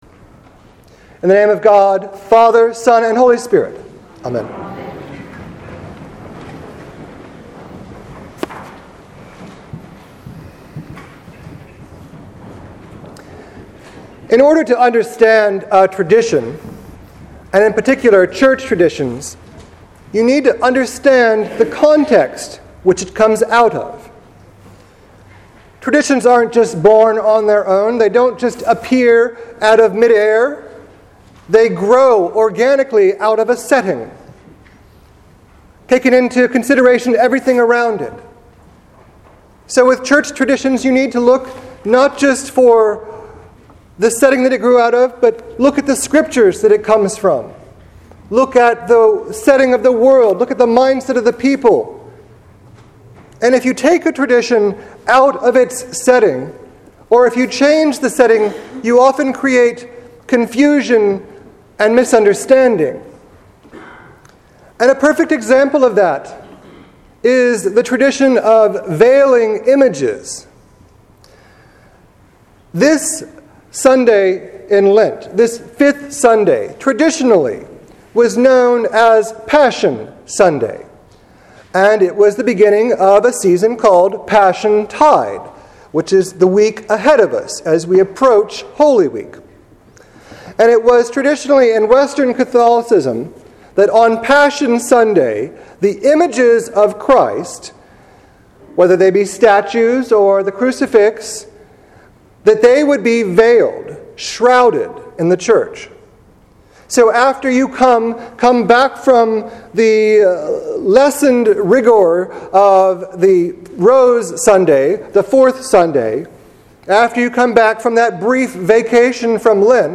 Sermon for March 22nd, 2015 Readings: Jeremiah 31:31-34 Psalm 51:1-13 Hebrews 5:5-10 John 12:20-33